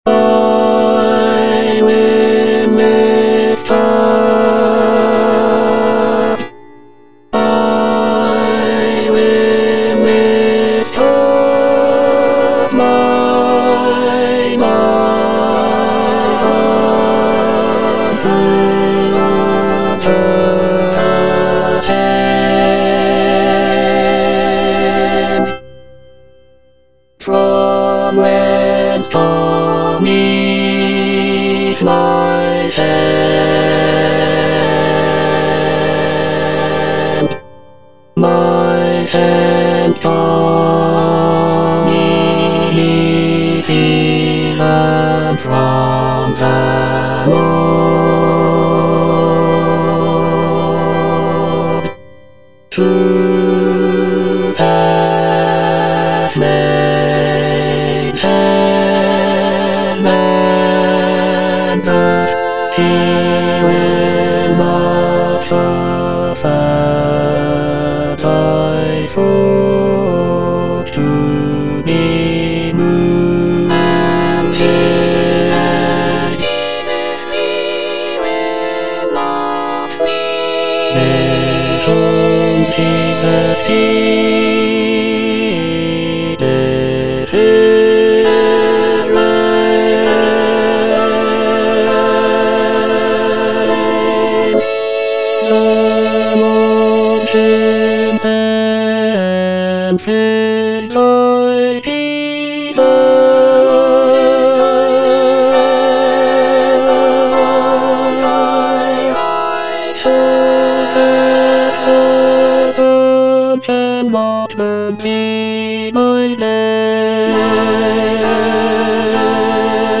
Bass I (Emphasised voice and other voices)